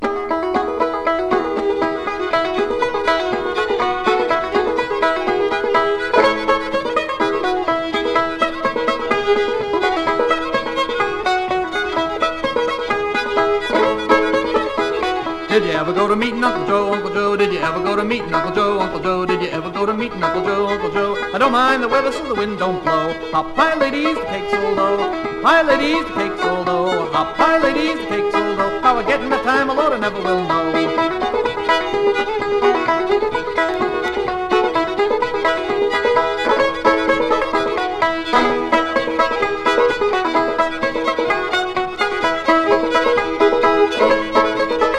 Folk, Bluegrass　USA　12inchレコード　33rpm　Mono